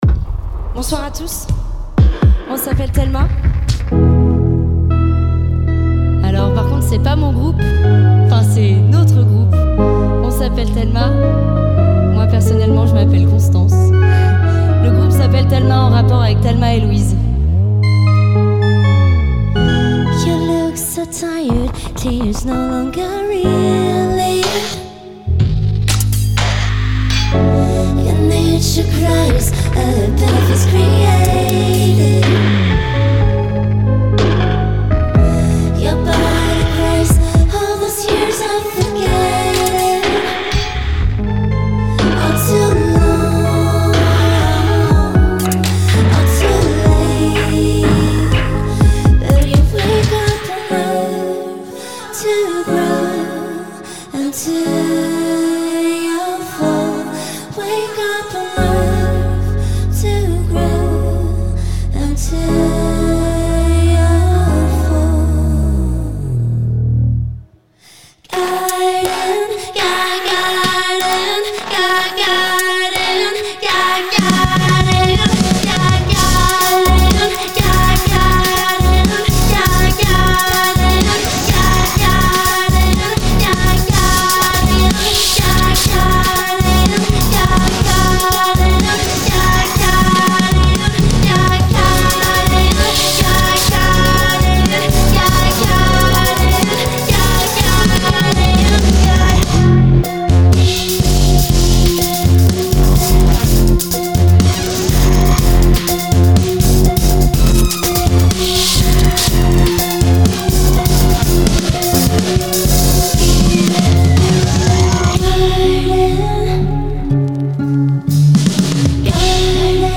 Pop Alternative, Electro